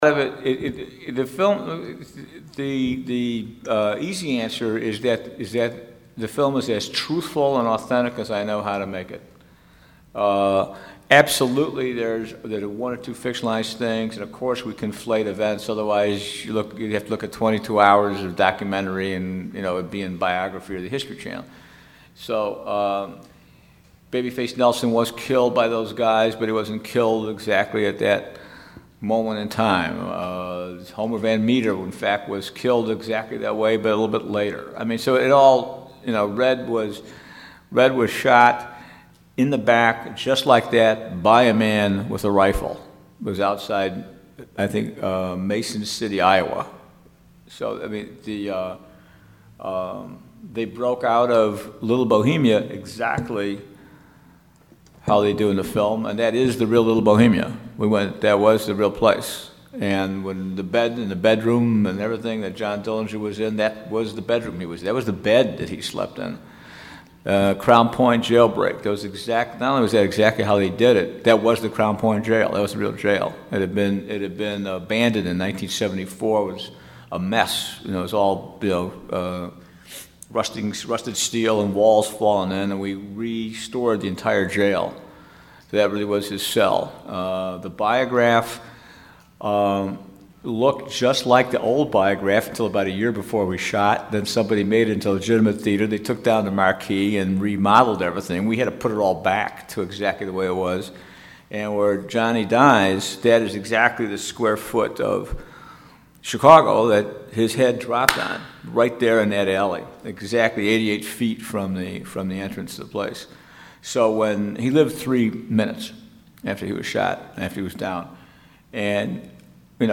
Public Enemies press junket